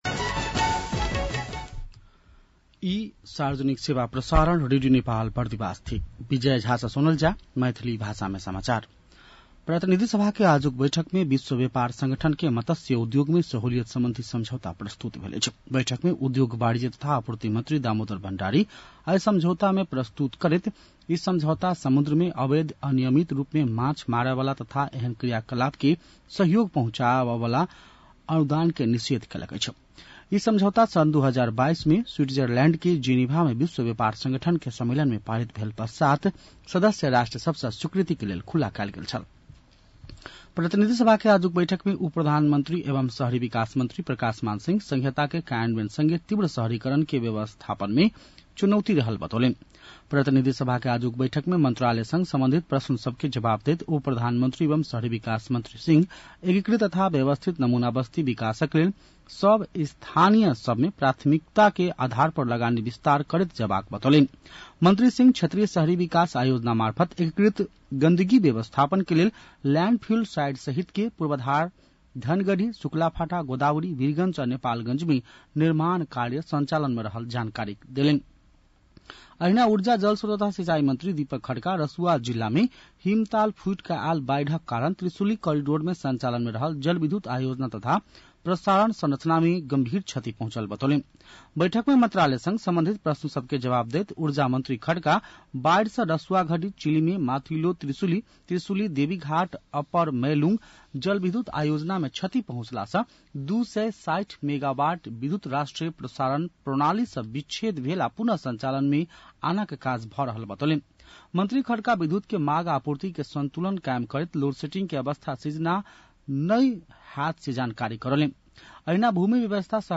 मैथिली भाषामा समाचार : ३० असार , २०८२
Maithali-news-3-30.mp3